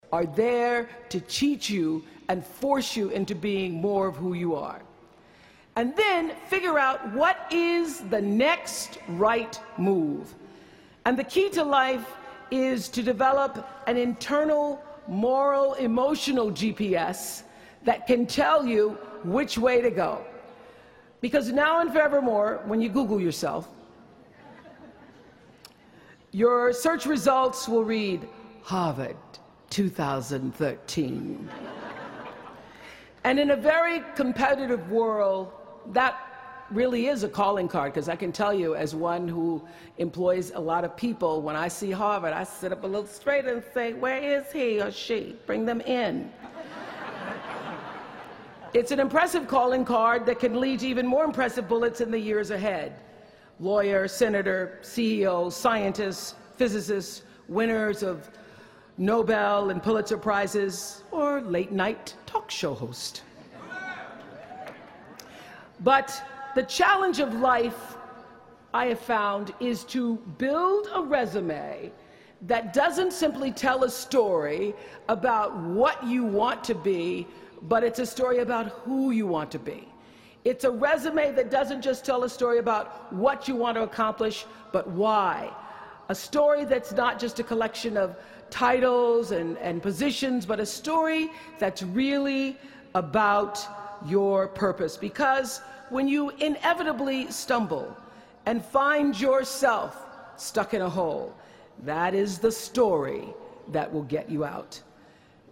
公众人物毕业演讲第355期:奥普拉2013在哈佛大学(7) 听力文件下载—在线英语听力室